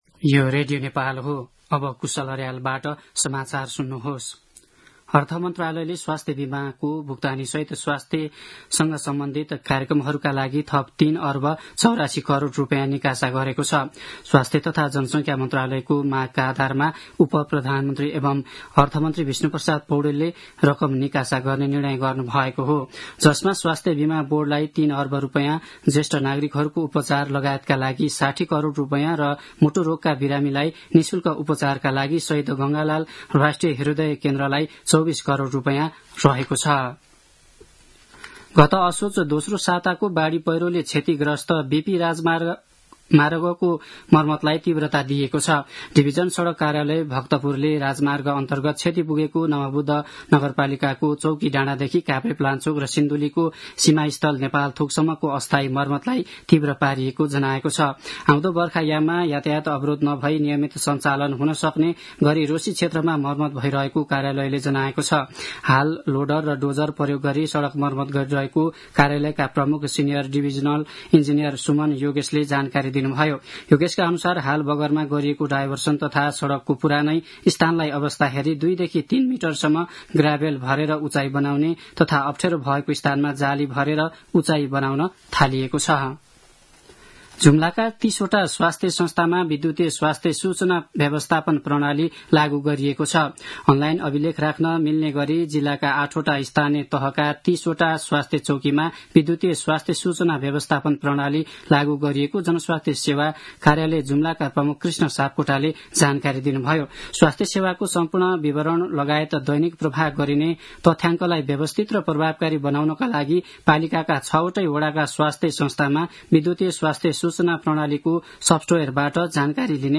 दिउँसो ४ बजेको नेपाली समाचार : २१ फागुन , २०८१
4-pm-news-1-1.mp3